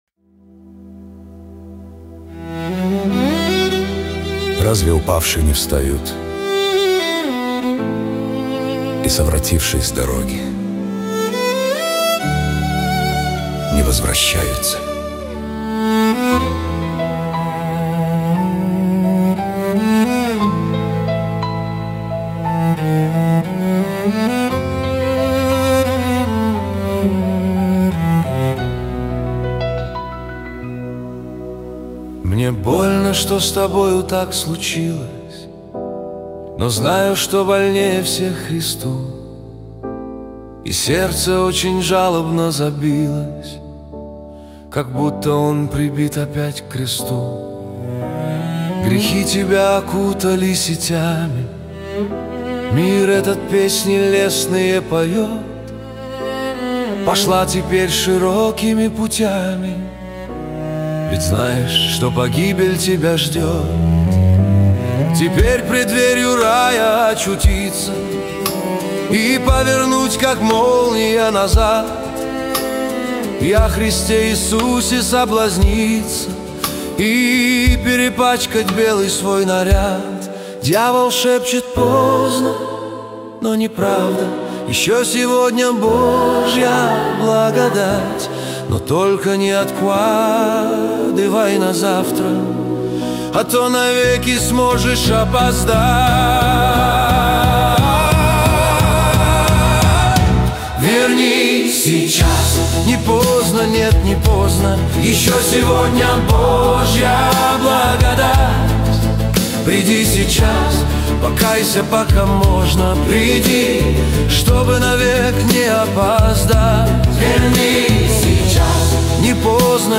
песня ai
1803 просмотра 1740 прослушиваний 579 скачиваний BPM: 59